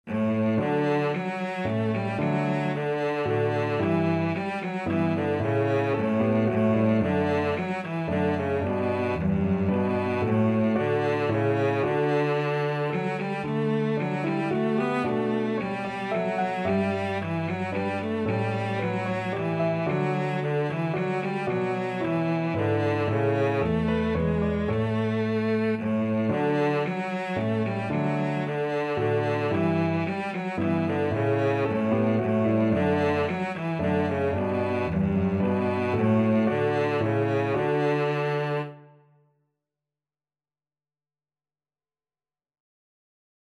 Cello
D major (Sounding Pitch) (View more D major Music for Cello )
3/4 (View more 3/4 Music)
Moderately Fast ( = c. 112)
Classical (View more Classical Cello Music)